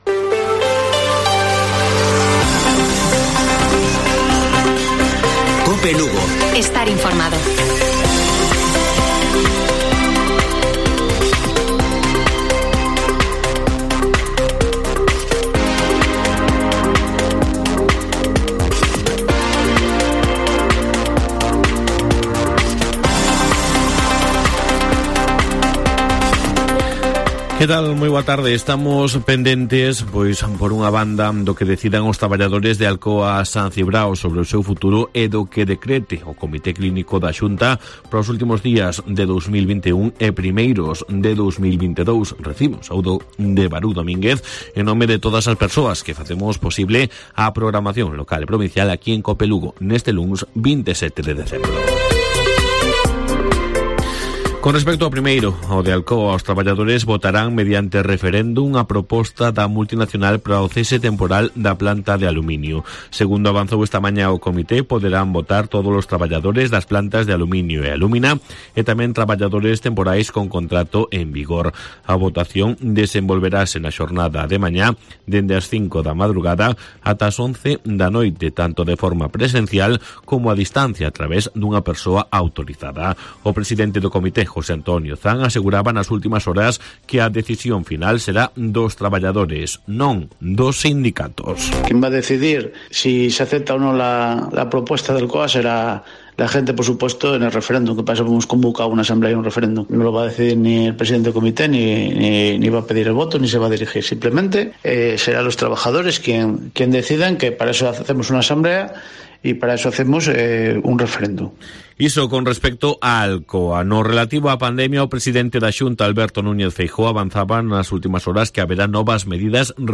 Informativo Mediodía de Cope Lugo. 27 de diciembre. 13:50 horas